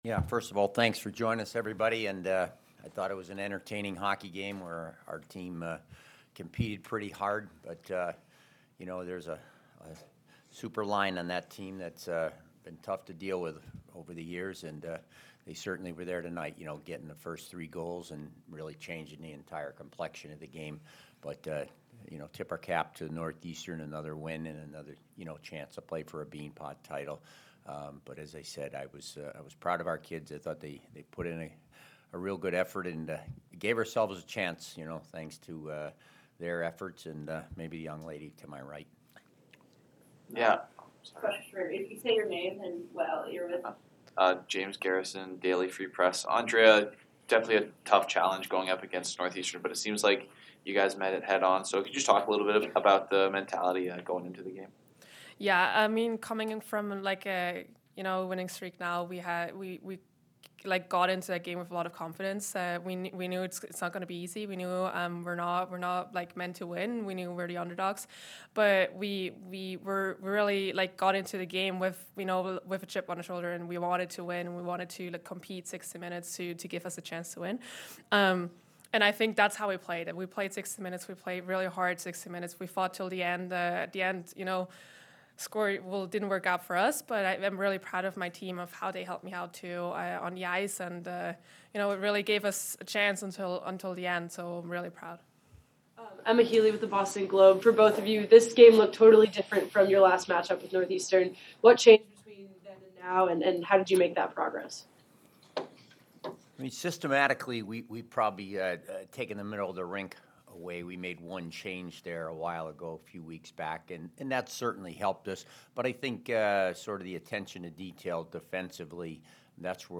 Women's Ice Hockey / Beanpot Semifinal Postgame Interview (2-7-23)